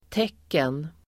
Uttal: [t'ek:en]